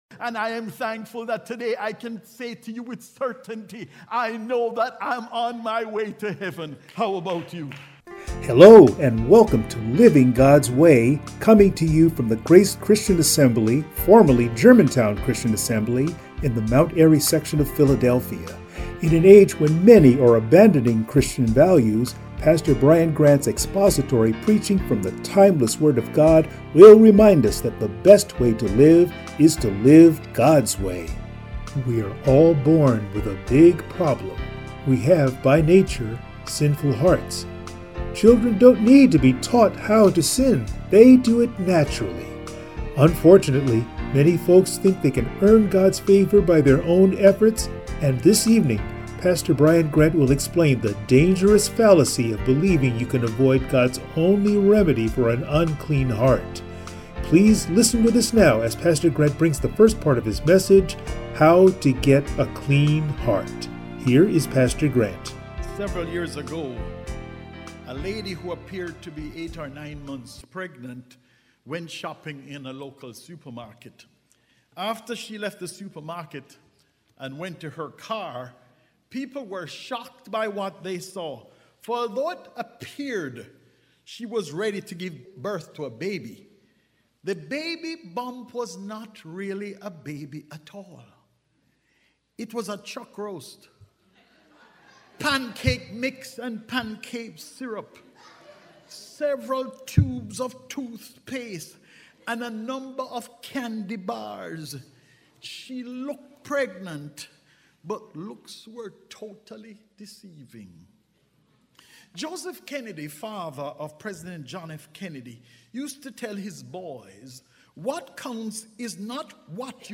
Matthew 23:13-26 Service Type: Sunday Morning Many folks think they can earn God’s favor by their own efforts.